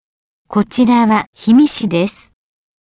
市内136か所に設置したスピーカーを通して、災害予防や災害対策などの情報を一斉に、より早く市民の皆さんに伝達することができるようになりました。